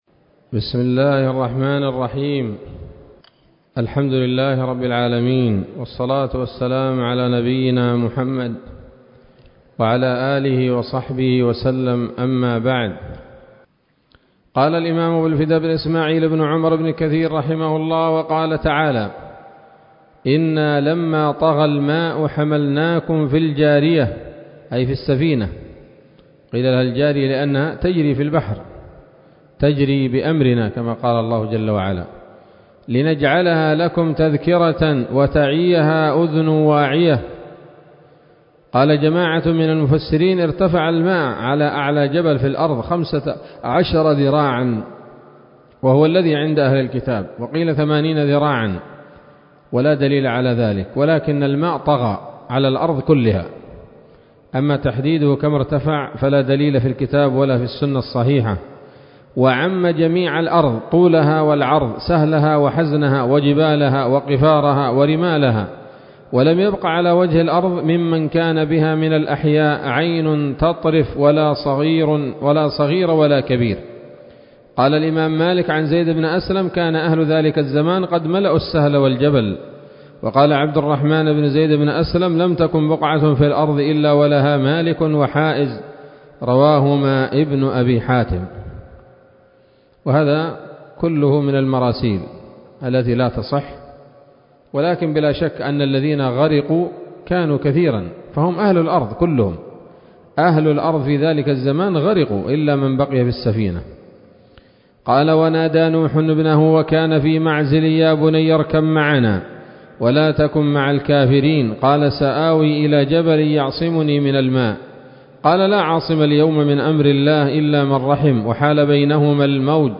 الدرس الخامس والعشرون من قصص الأنبياء لابن كثير رحمه الله تعالى